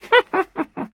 Minecraft Version Minecraft Version snapshot Latest Release | Latest Snapshot snapshot / assets / minecraft / sounds / entity / witch / ambient1.ogg Compare With Compare With Latest Release | Latest Snapshot